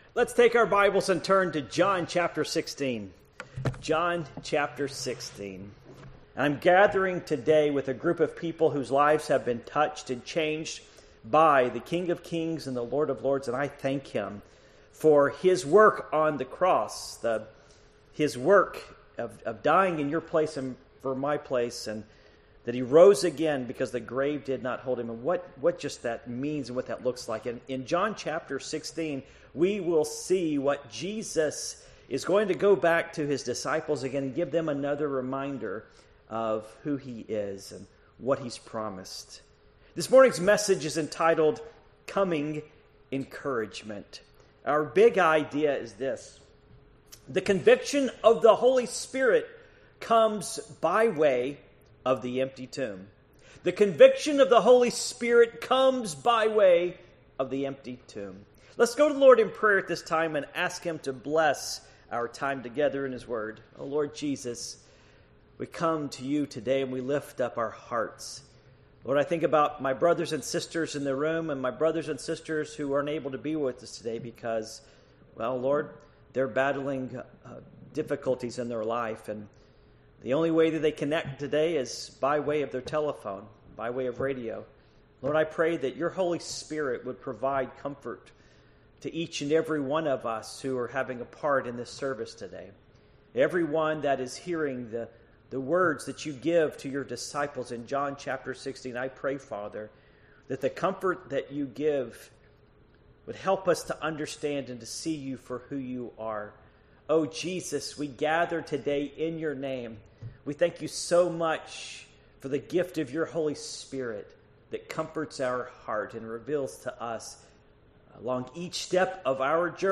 Series: The Ministry of the Encourager Passage: John 16:1-11 Service Type: Morning Worship